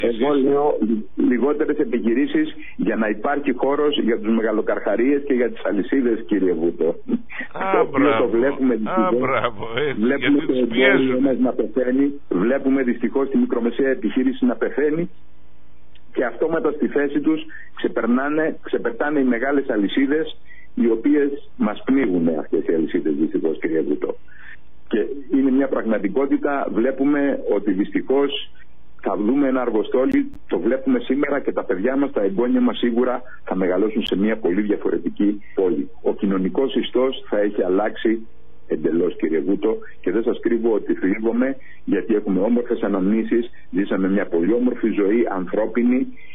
ξέσπασε στην ραδιοφωνική εκπομπή